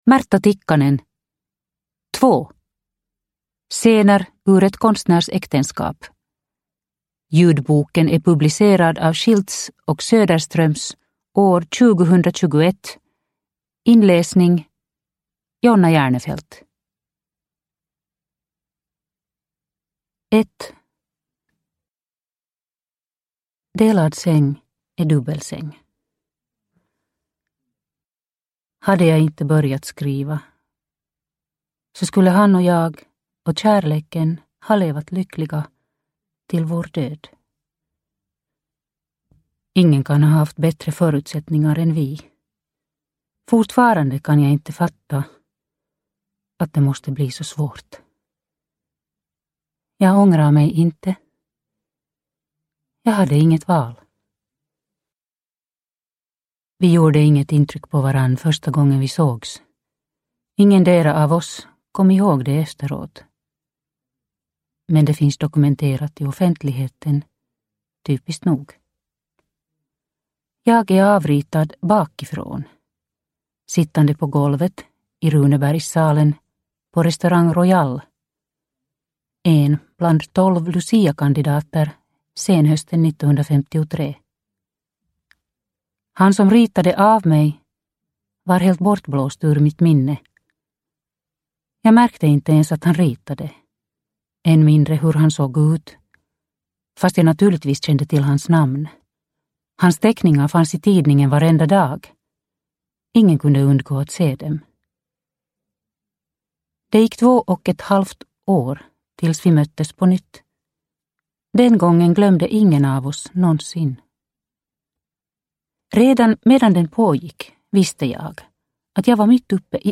Två – Ljudbok – Laddas ner